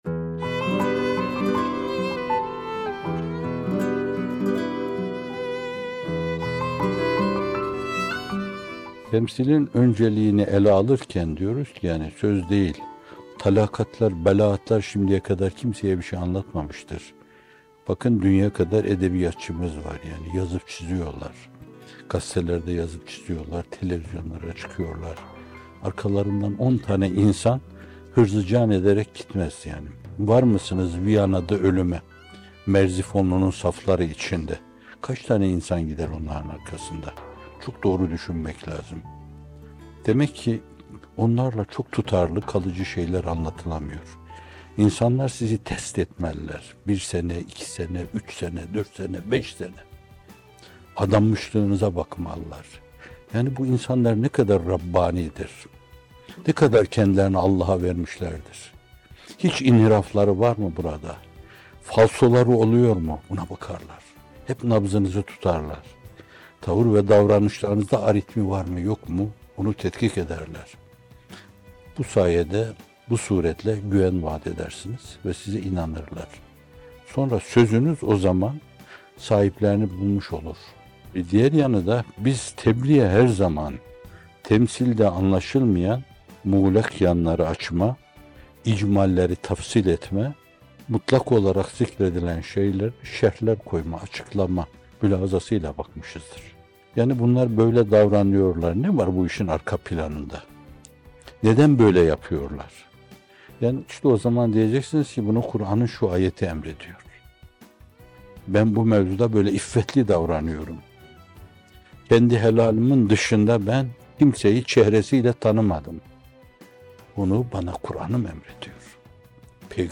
Bir Nefes (84) – Temsil Öncelikli Tebliğ - Fethullah Gülen Hocaefendi'nin Sohbetleri